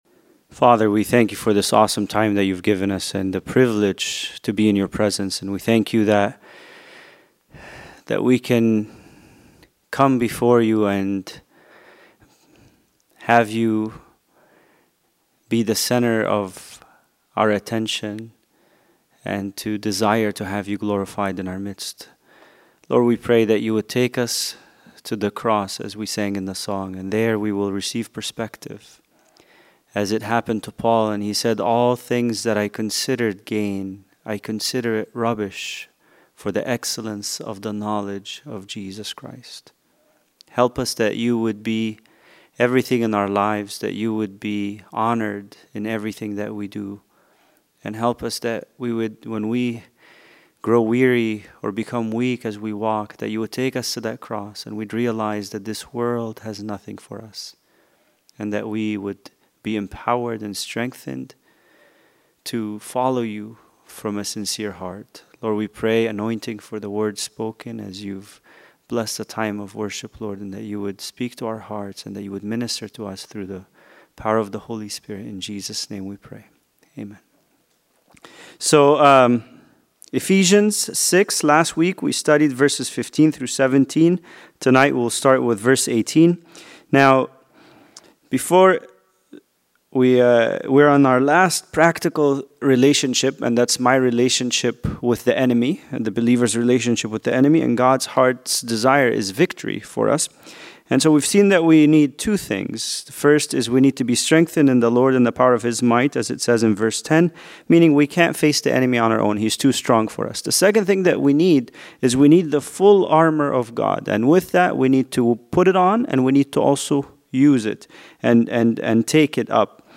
Bible Study: Ephesians 6:18A